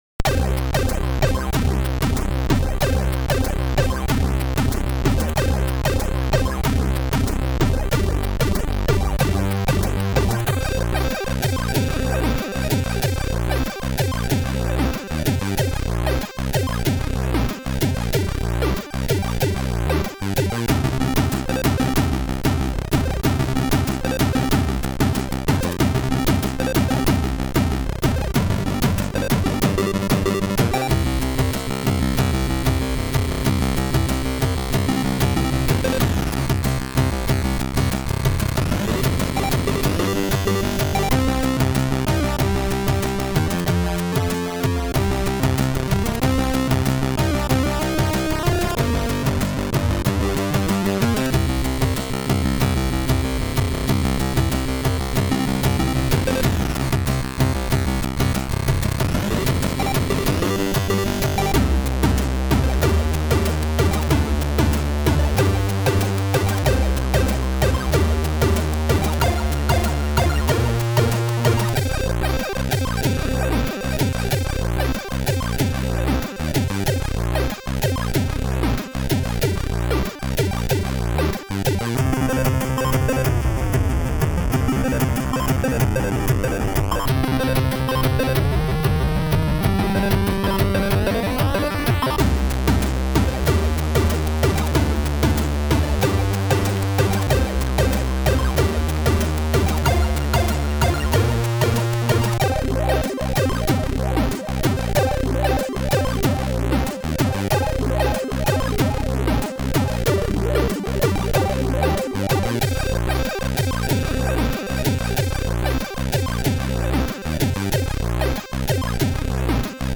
SID Version: 8580 (PAL)
Another very hard hitting loader sountrack.